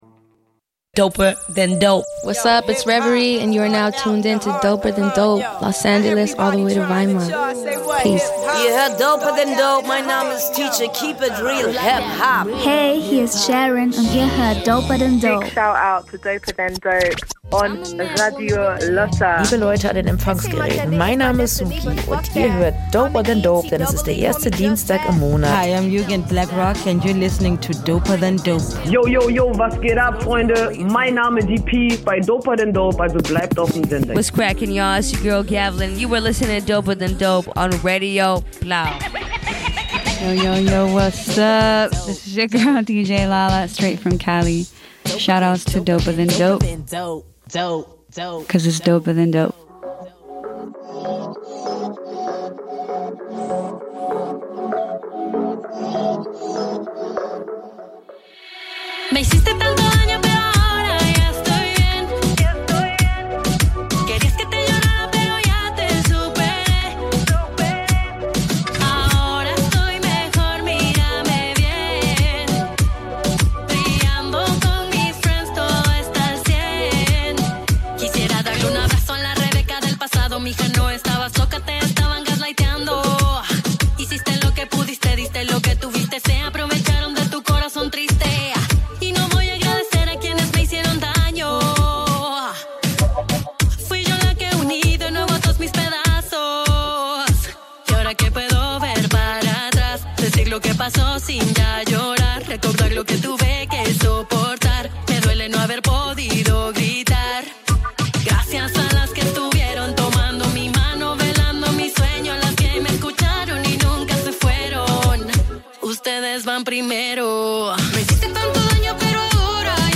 Bei doper than dope wird HipHop-Kultur gefeiert! Dabei werden auch Entwicklungen und wichtige Releases im HipHop Mainstream angeschaut, der Fokus liegt aber vor allem auf Sub-Szenen und Artists, die oft zu wenig Beachtung finden, wie Frauen und queere Rapper*innen.